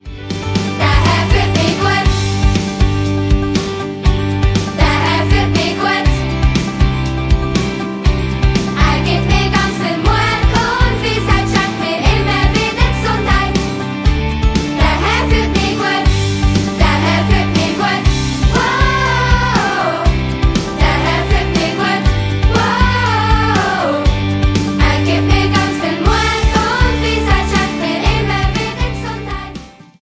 Mundartworship für Kids und Preetens